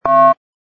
sfx_ui_react_accept01.wav